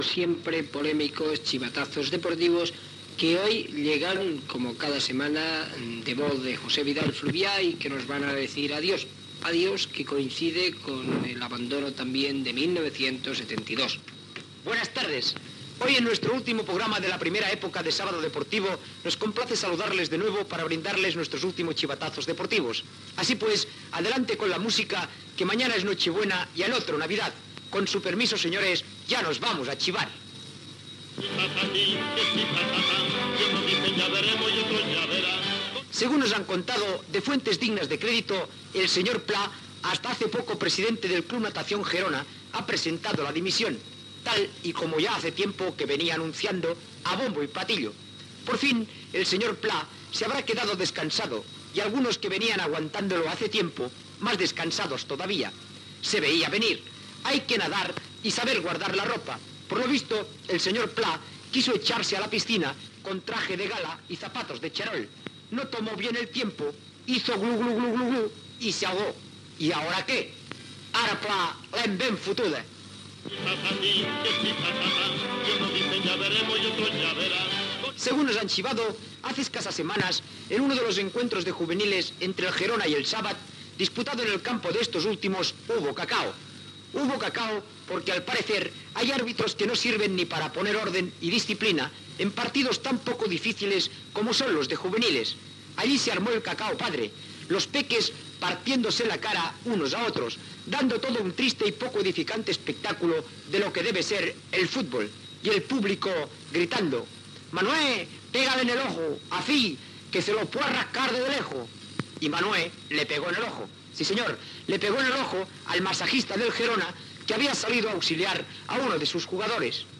Careta del programa, temes més significatius tractats al programa des del mes de maig de 1972, sumari de continguts, publicitat, resum informatiu setmanal: la tercera setmana de l'esport gironí delebrada a Olot.
Esportiu